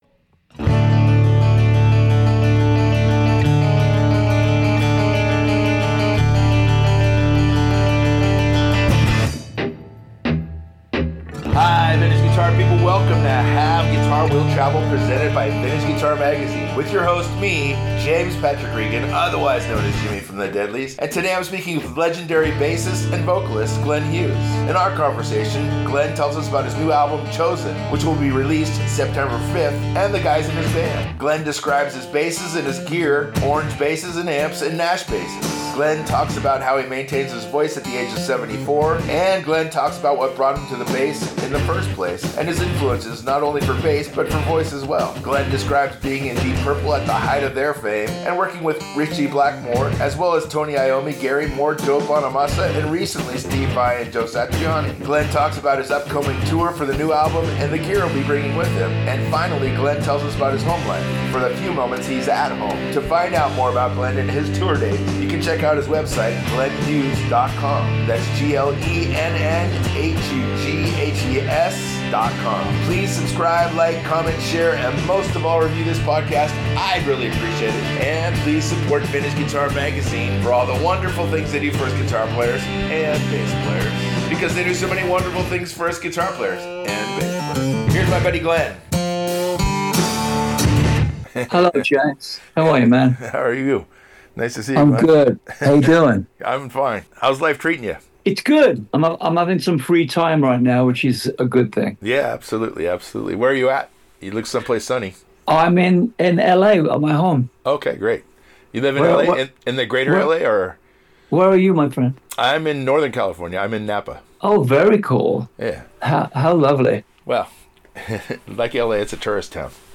speaks with legendary bassist and vocalist Glenn Hughes